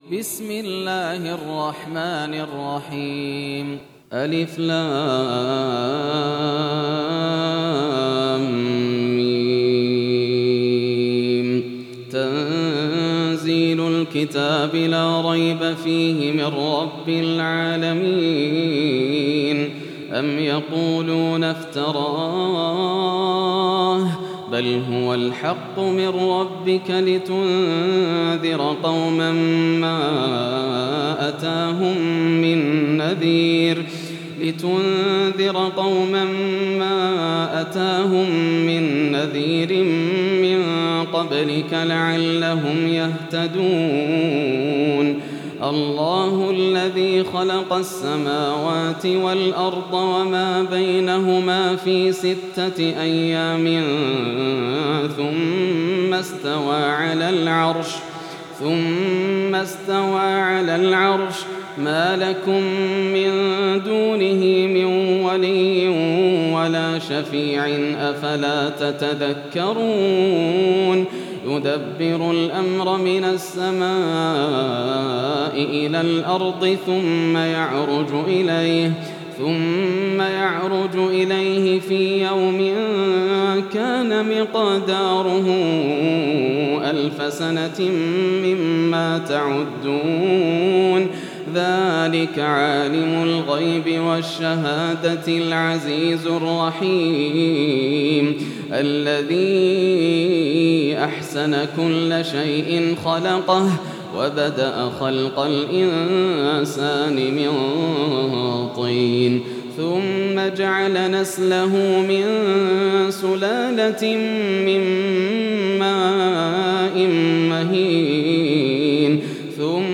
سورة السجدة > السور المكتملة > رمضان 1433 هـ > التراويح - تلاوات ياسر الدوسري